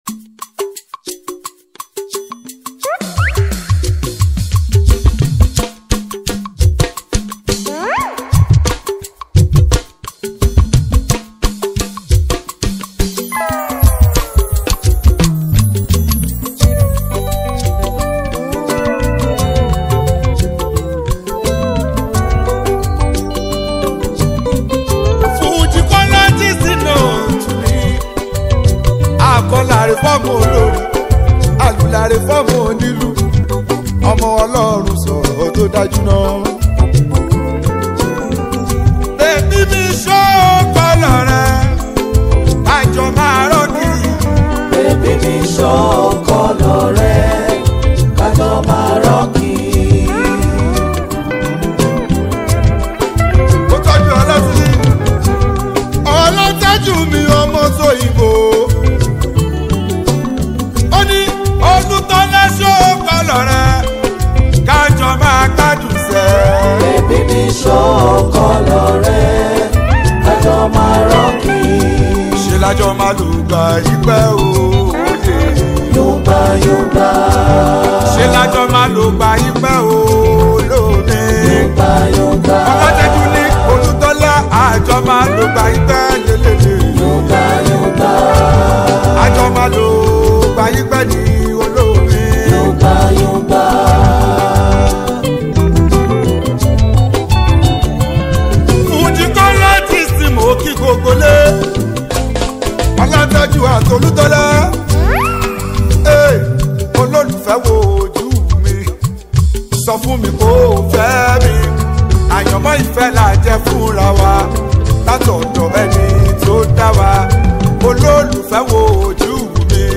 especially people with so much love for Yoruba Fuji Music.